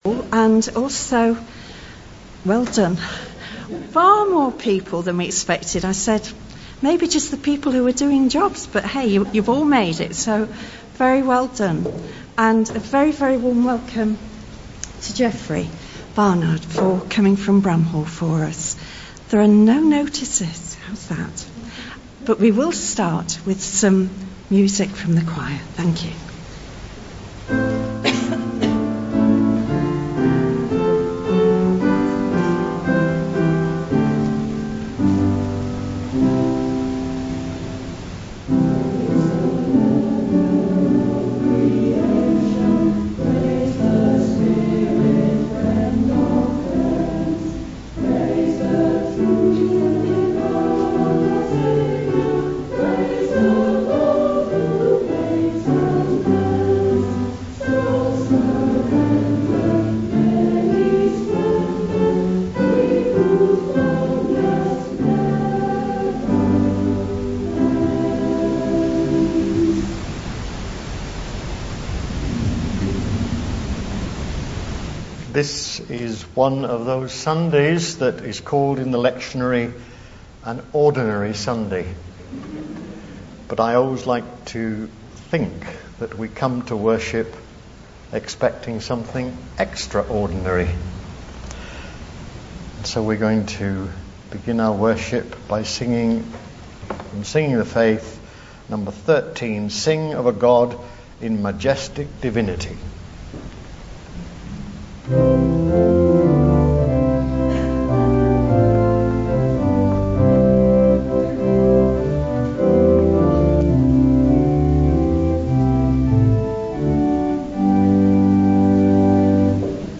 2020-02-09 Communion
Genre: Speech.